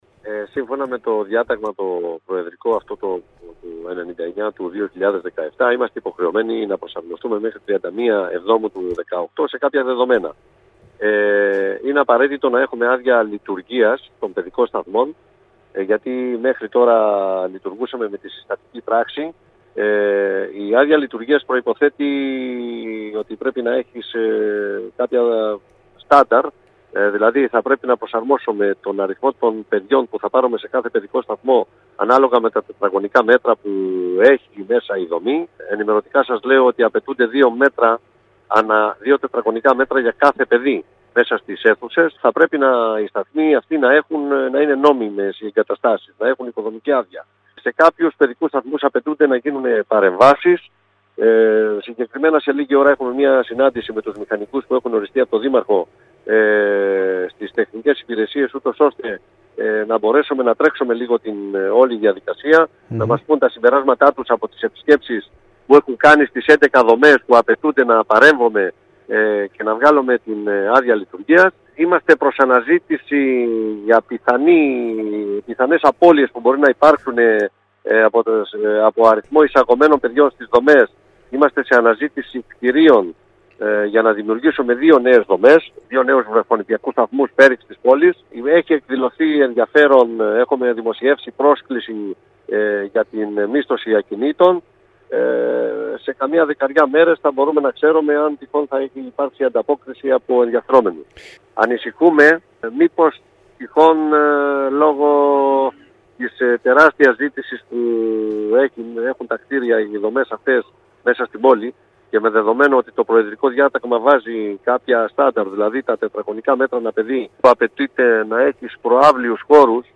Ακούστε τμήμα των δηλώσεων του κ. Σωτ. Καζιάνη στον σύνδεσμο που ακολουθεί: